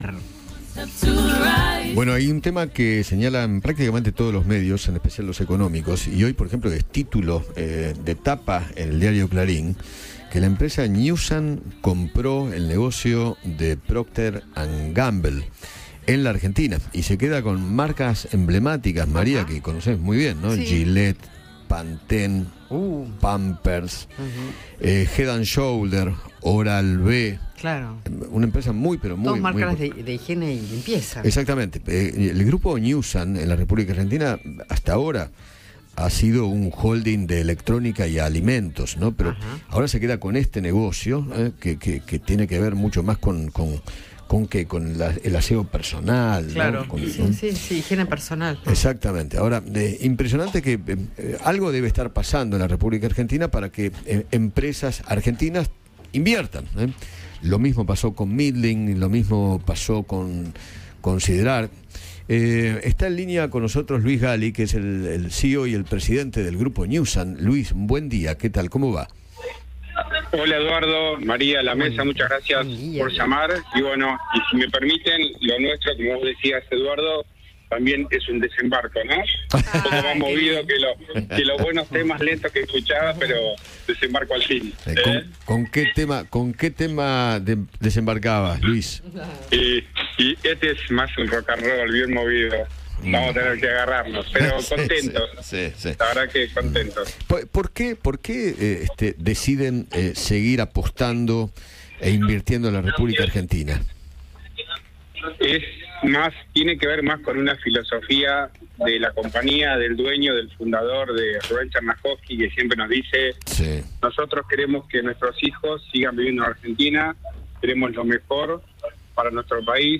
Eduardo Feinmann: “¿Cómo están viendo el país, la economía, estos primeros 6 meses del gobierno de Javier Milei?”